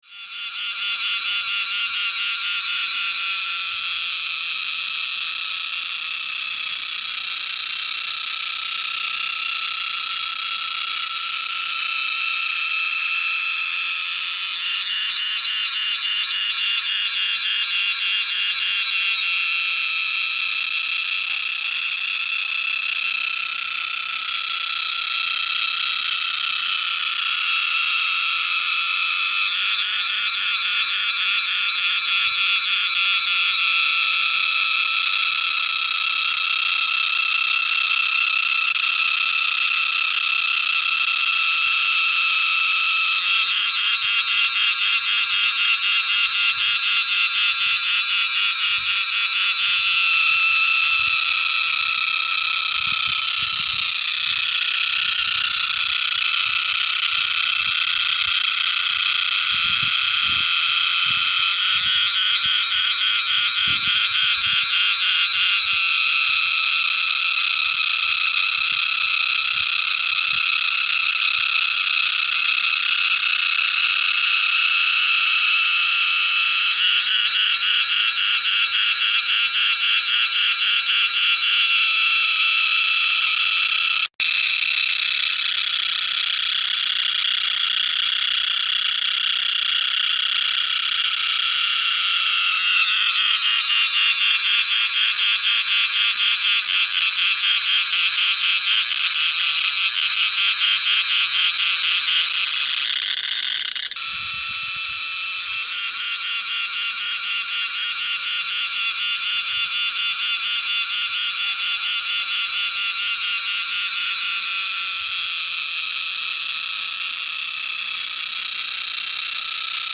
เสียงจั๊กจั่น mp3
หมวดหมู่: เสียงสัตว์ป่า
คำอธิบาย: เสียงจั๊กจั่นสำหรับเปิดล่อเข้าดีมากเสียงนี้เป็นเสียงล่อ จั๊กจั่น ซึ่งเป็นนักร้องที่เสียงดีที่สุดในโลก เสียงธรรมชาติที่มีความชัดเจน โดยสามารถดาวน์โหลดเสียง จั๊ก จั่น mp3 4sh ได้ สำหรับคนที่ต้องการฟังเสียงจั๊กจั่นร้องตอนกลางคืน หรือเสียงจั๊กจั่นหน้าร้อน แน่นอนว่าเสียงจั๊กจั่นเร้าใจจะเป็นเสียงที่ชัดเจน 100% และไม่มีวิศวกรรมอย่างตรงไปตรงมา การฟังเสียงจั๊กจั่นร้องกันเองจะเป็นประสบการณ์ที่น่าตื่นเต้นและน่าตื่นเต้นไปกับเสียงธรรมชาติของจั๊กจั่น